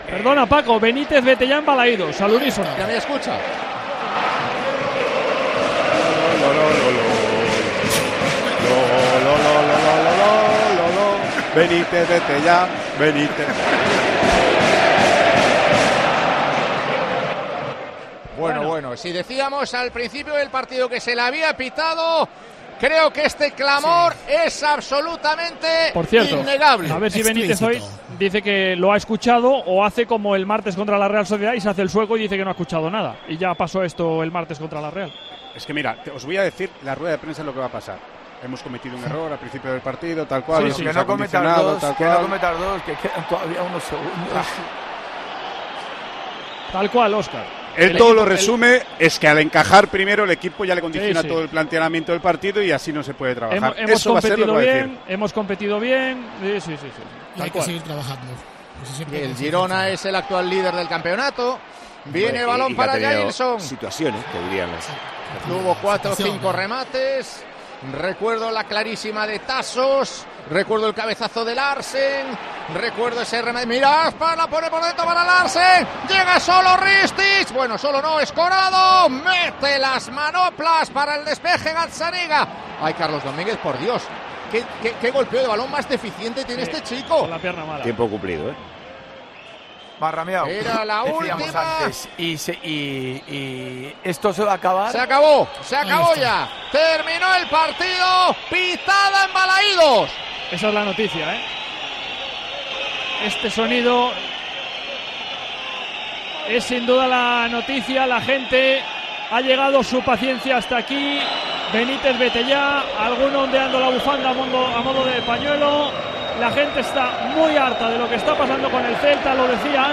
Óscar Pereiro se une a los aficionados de Balaídos y canta el "Benítez vete ya" en Tiempo de Juego